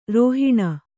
♪ rōhiṇa